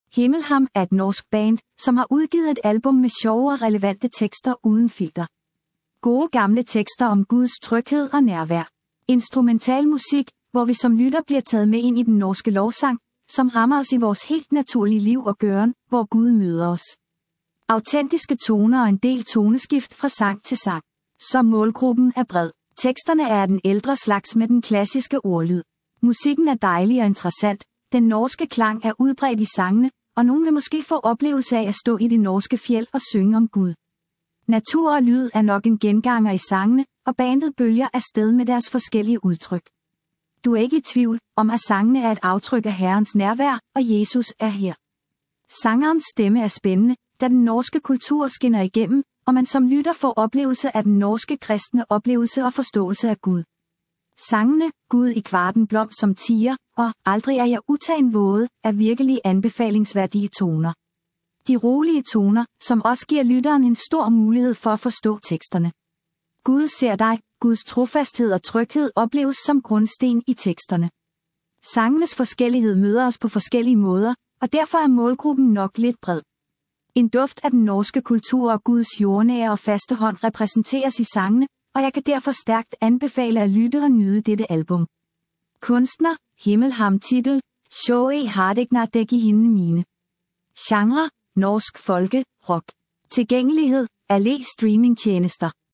Autentiske toner og en del toneskift fra sang til sang.
Genre: Norsk folke/rock